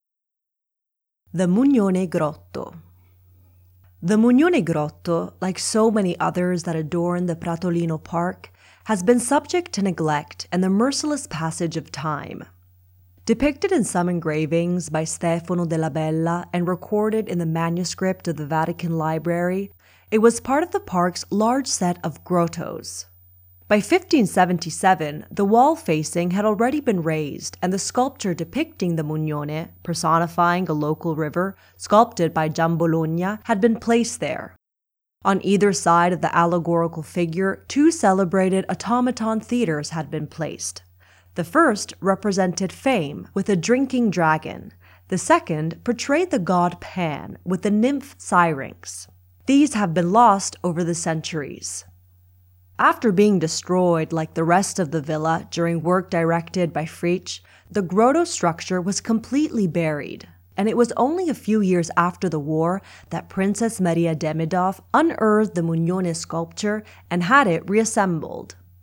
Audioguide of the Medici Park of Pratolino